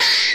squirrel01.ogg